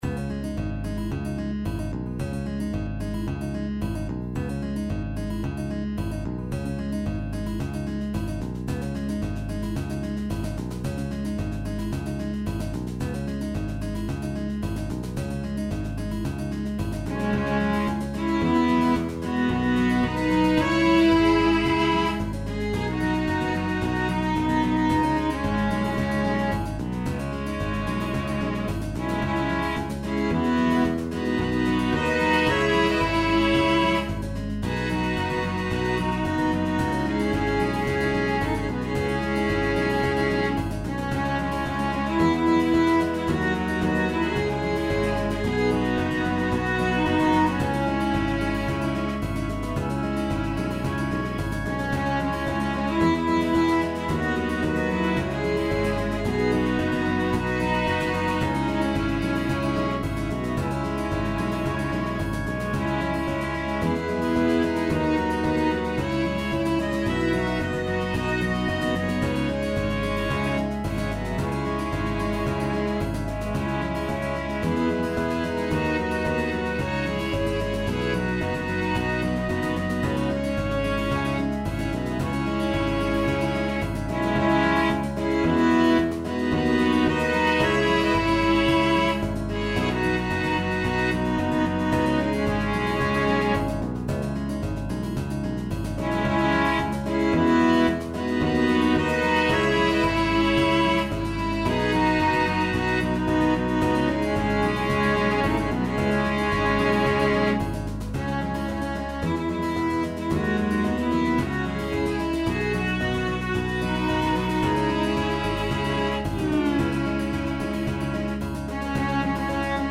SSAA + piano/band